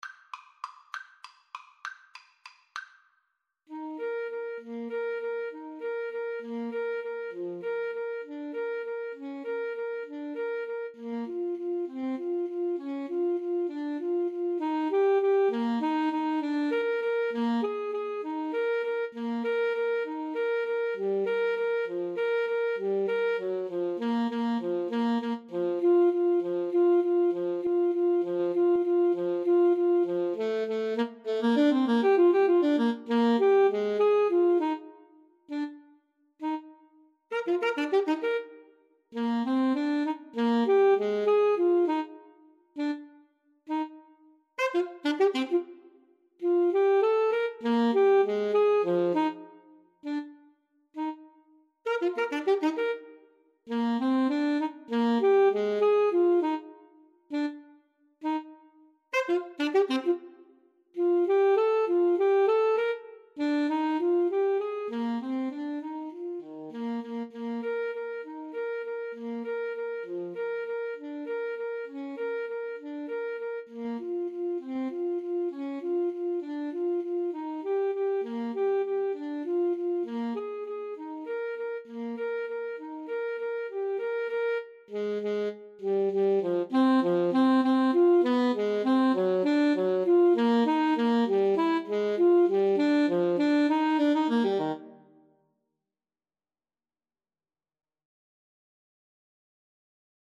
Free Sheet music for Alto Saxophone Duet
Eb major (Sounding Pitch) Bb major (French Horn in F) (View more Eb major Music for Alto Saxophone Duet )
One in a bar c. .=c.66
3/4 (View more 3/4 Music)
Classical (View more Classical Alto Saxophone Duet Music)